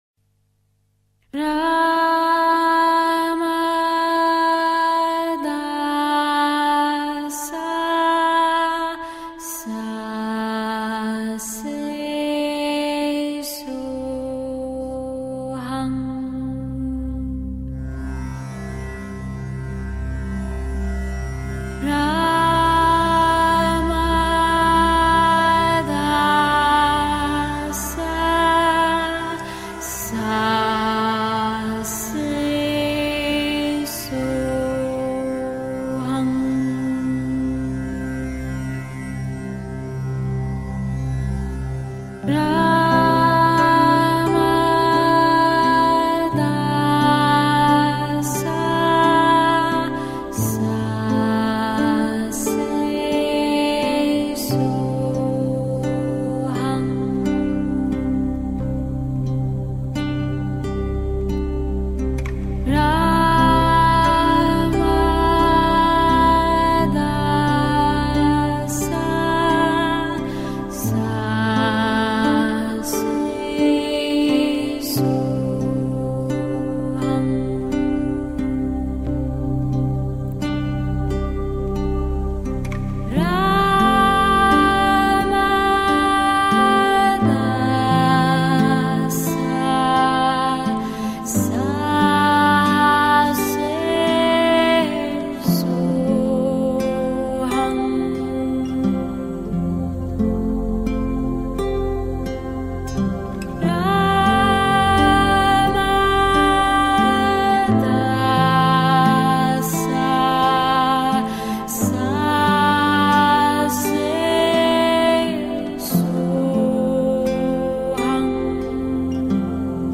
CHANTS REIKI